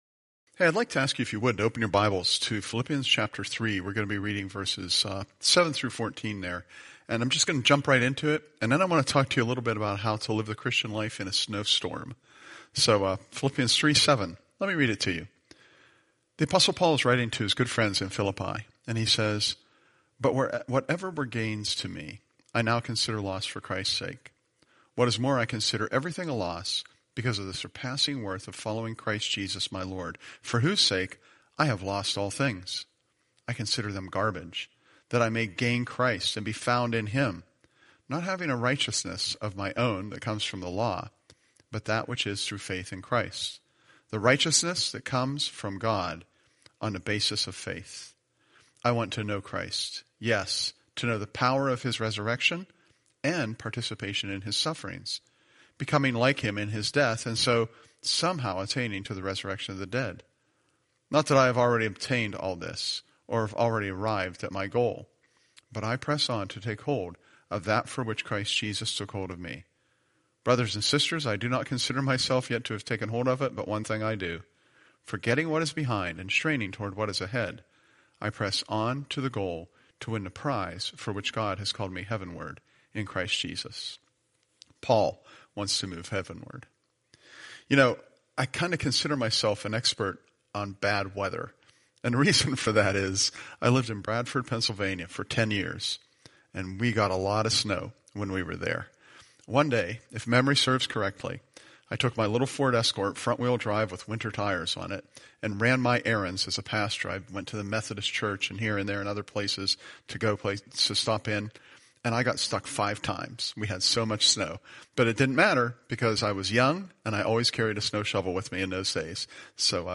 Staying Safe in a Snowstorm – Curwensville Alliance Church Podcasts